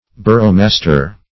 Search Result for " boroughmaster" : The Collaborative International Dictionary of English v.0.48: Boroughmaster \Bor"ough*mas"ter\, n. [Cf. Burgomaster .] The mayor, governor, or bailiff of a borough.
boroughmaster.mp3